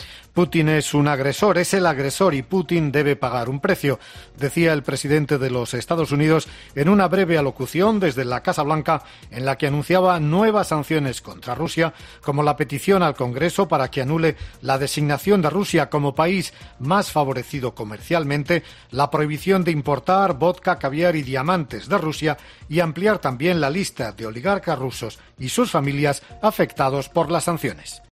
En una intervención televisada desde la Casa Blanca, Biden dio más detalles sobre la decisión adelantada ayer de poner fin a su trato comercial favorable con Rusia